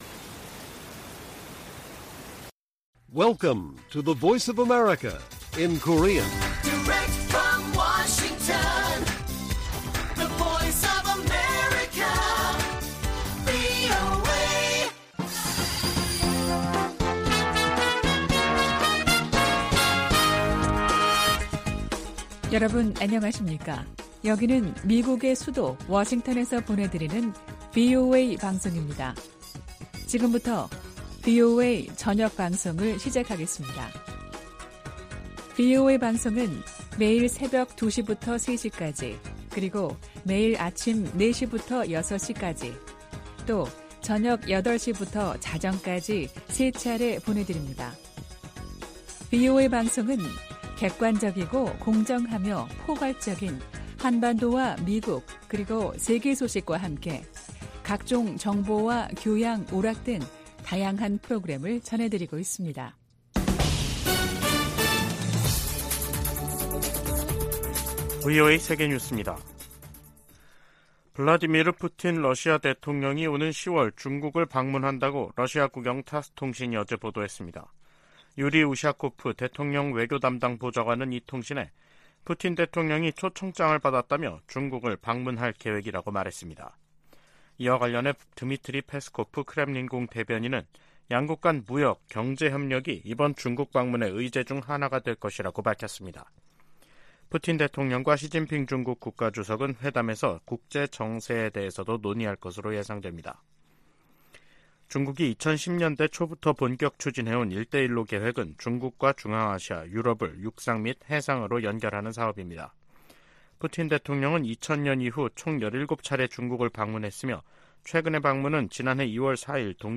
VOA 한국어 간판 뉴스 프로그램 '뉴스 투데이', 2023년 7월 26일 1부 방송입니다. 북한의 '전승절' 행사에 중국과 러시아 대표단이 참가하면서 북중러 3각 밀착이 선명해지는 것으로 분석되고 있습니다. 미 국무부는 중국과 러시아가 북한의 불법 활동을 자제하는 역할을 해야 한다고 강조했습니다. 미 국방부는 월북한 미군 병사와 관련해 아직 북한 측의 응답이 없다고 밝혔습니다.